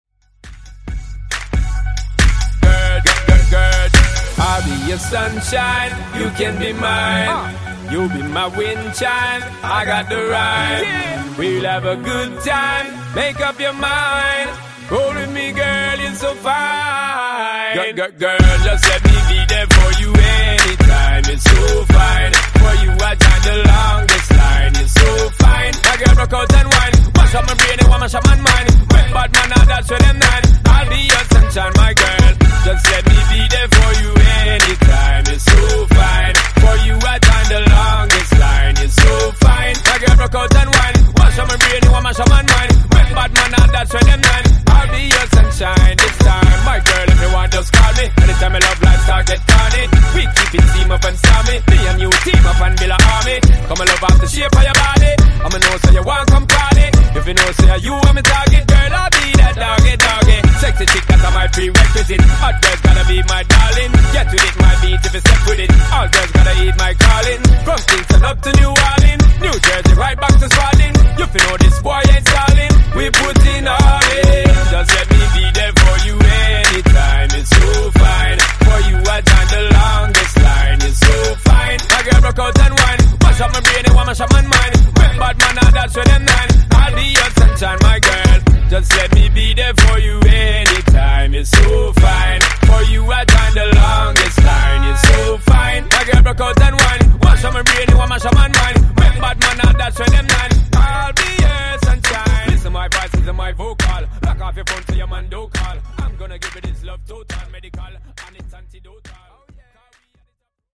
Genres: RE-DRUM , REGGAETON Version: Clean BPM: 100 Time